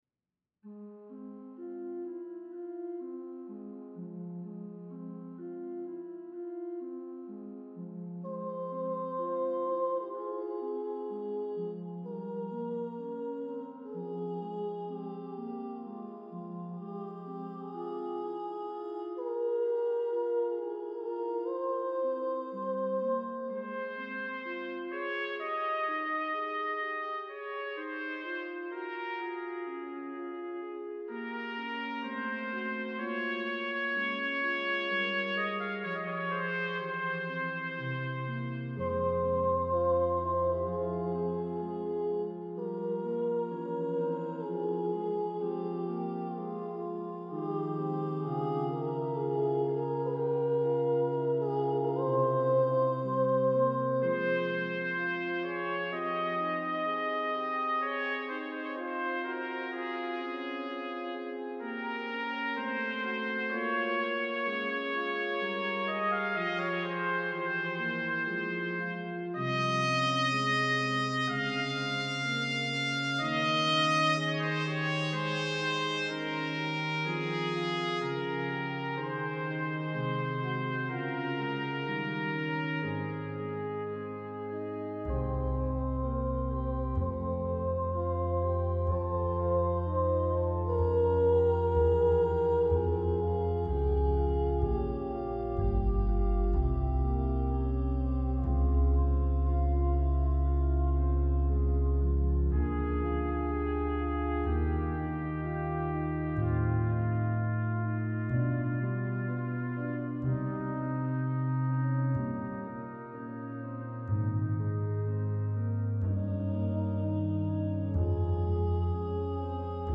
Her tone is just great, right on key, and free of that nasty vibrato that (to my mind) mars so much of trained singing, and doubly so for sopranos.
I used some sampled instruments and sound effects for accompaniment and variety.
Her singing here is wordless, but all the songs originally had words in Latin, German, English, Portuguese, and French.